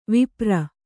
♪ vipra